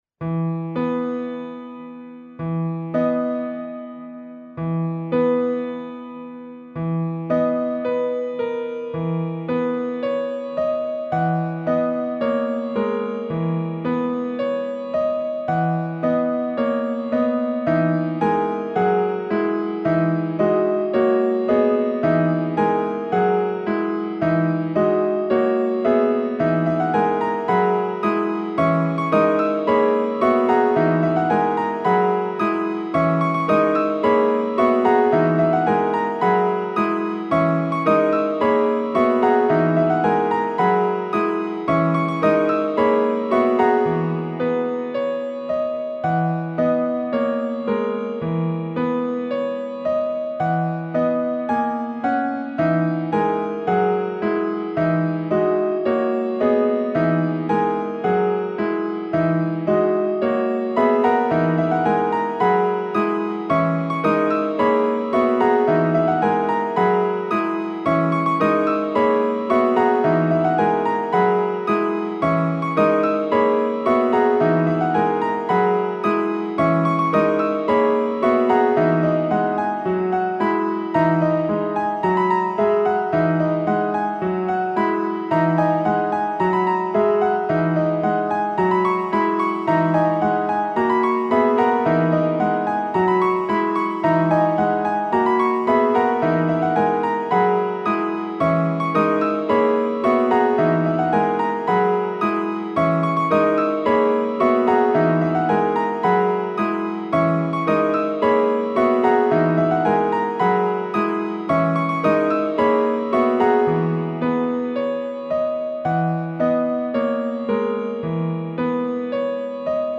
Solo Piano in E Flat Minor